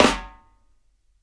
SNARE FLAM.wav